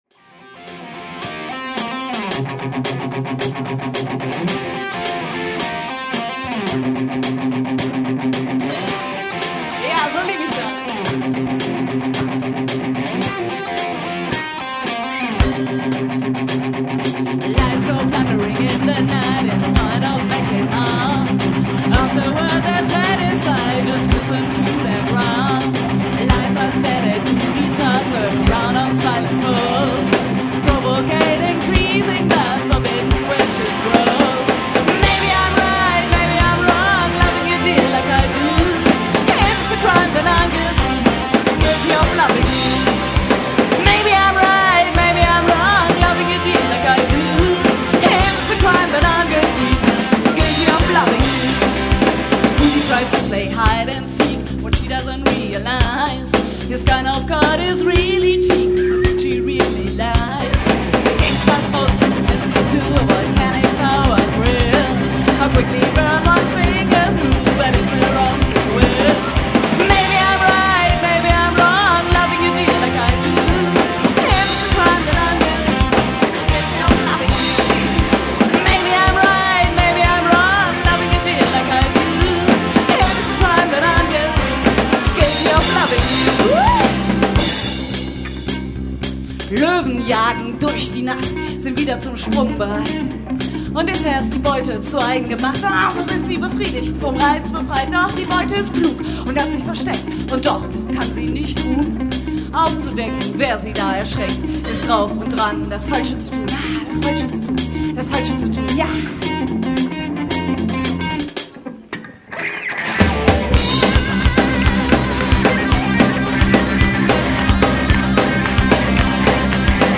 live
Gesang
Drums
Bass
Keys
Gitarre